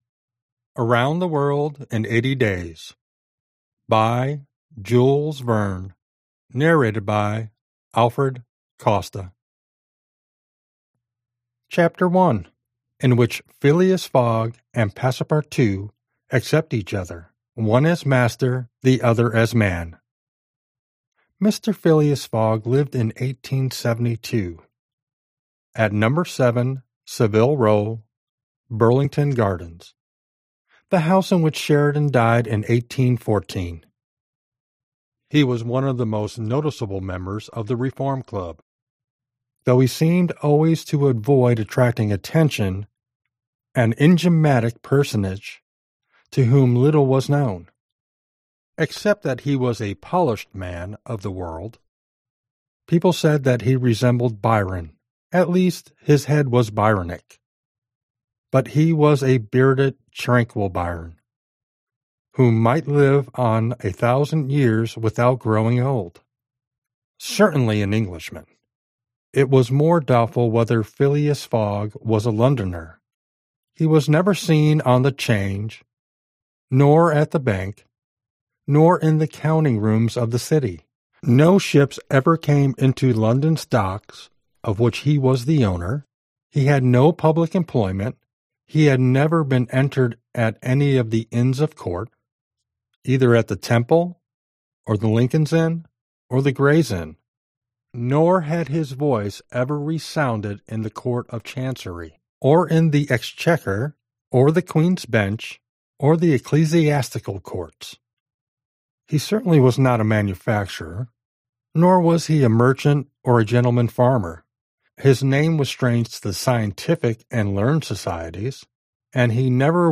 Аудиокнига Around the World in 80 Days | Библиотека аудиокниг